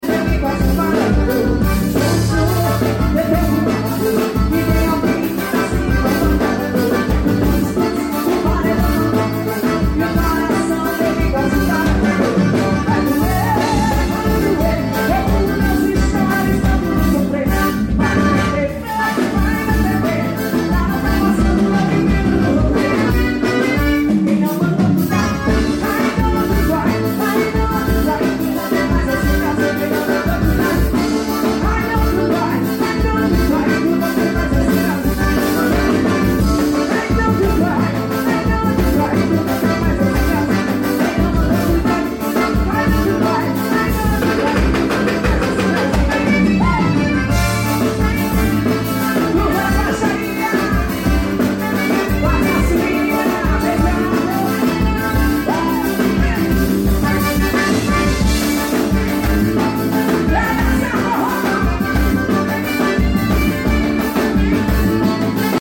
forró